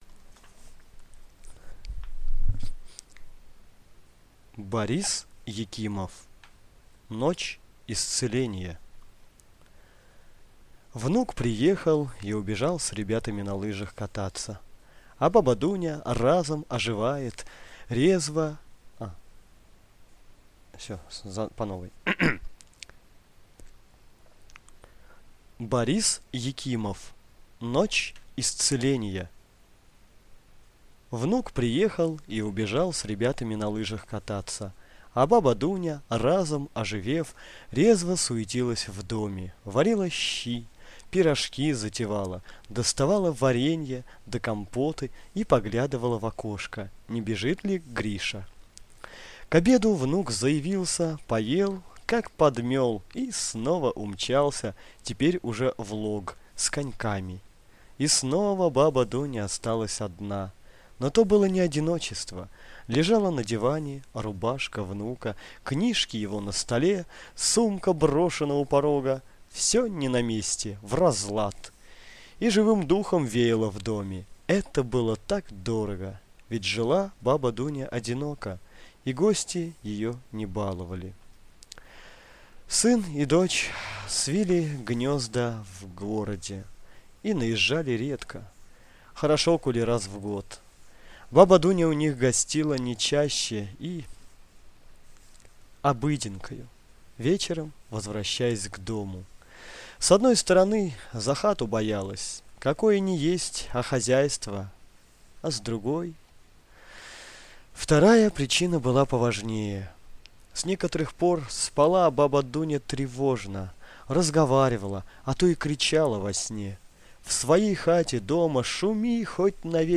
Ночь исцеления - аудио рассказ Екимова - слушать онлайн